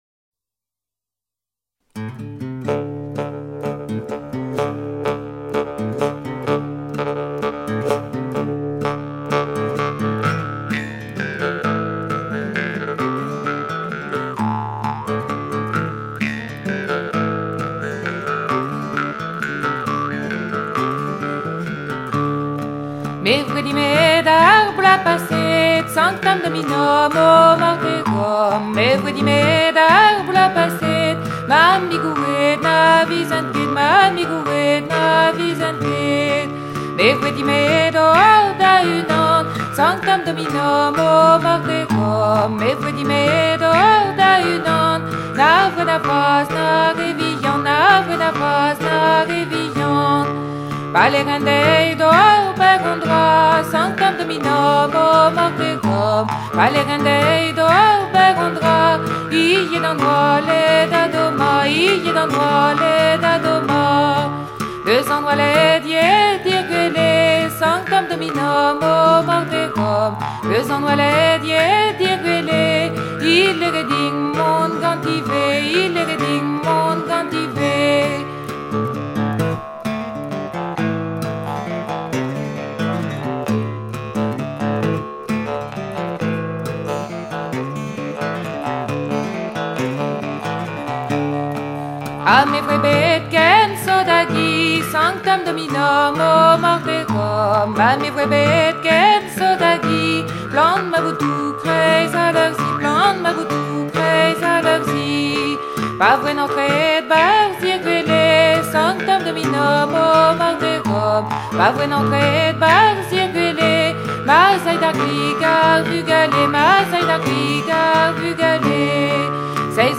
Chansons maritimes du Pays bigouden